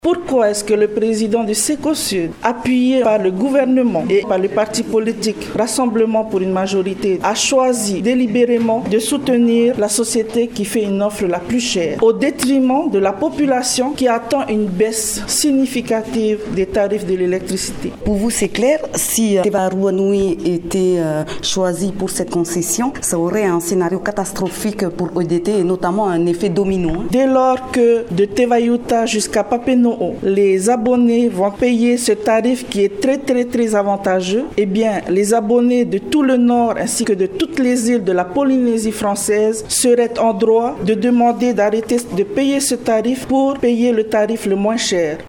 Le président du groupe UPLD à l’assemblée, Antony Géros, et l’élue, Valentina Cross, ont dénoncé jeudi matin lors d’une conférence de presse les « pressions » et « tractations politiques » à l’œuvre selon eux pour favoriser EDT dans l’appel d’offre concernant l’attribution du marché de la distribution de l’électricité au Sud de Tahiti. Les élus s’étonnent que la plainte déposée en août dernier n’ait pas abouti.
L’UPLD a invité les médias jeudi matin dans leurs locaux.